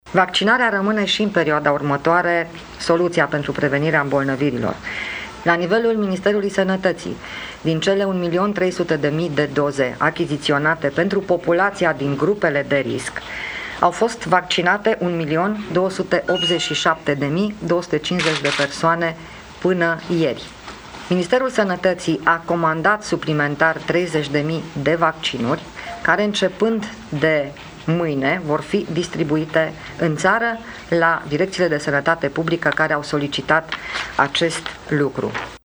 Ministrul Sănătății a enumerat măsurile care trebuie luate în cazul declarării epidemiei de gripă: